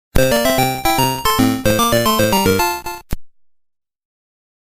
Game Over jingle